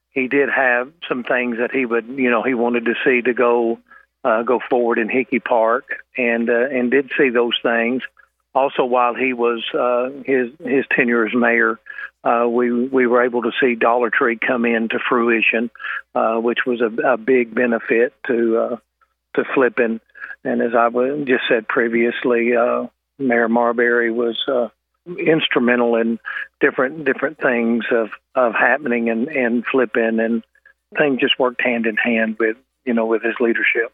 Hogan worked with Marberry as a former member of the Flippin City Council, and he says they shared a similar vision for the city.